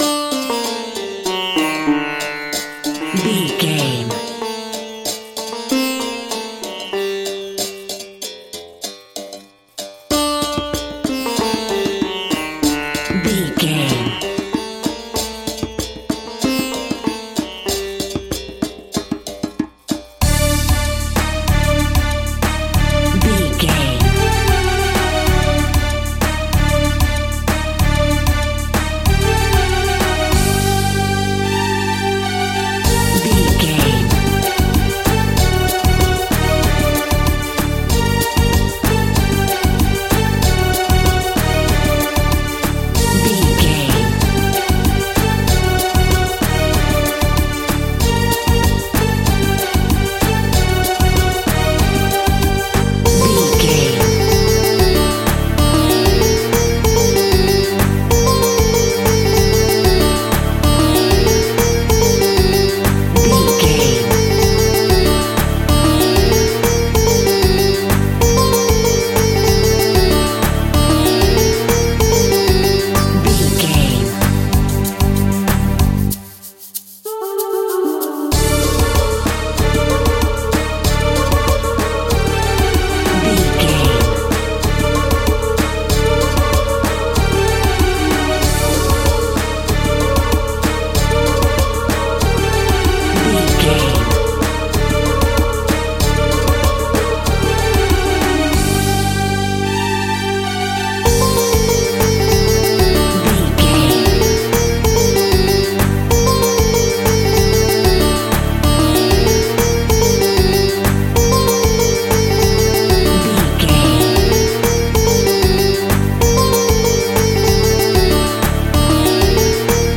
Aeolian/Minor
World Music
percussion
congas
kora
djembe